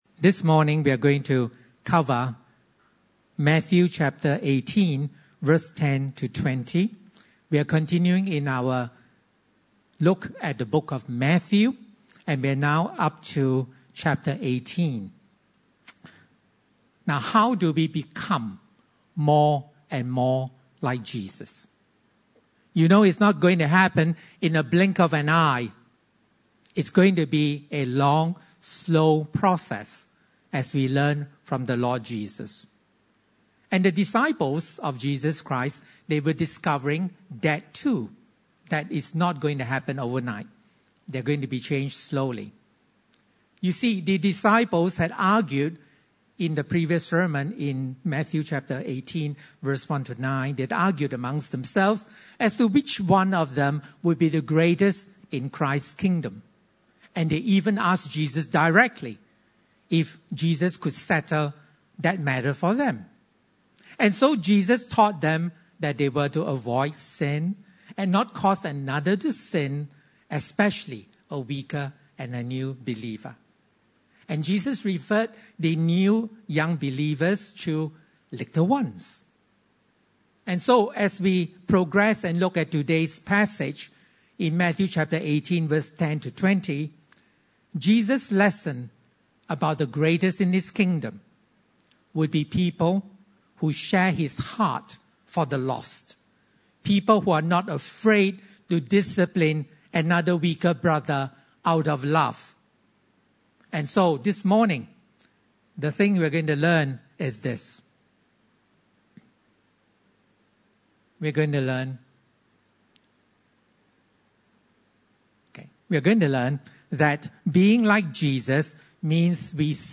Passage: Matthew 18:10-20 Service Type: Sunday Service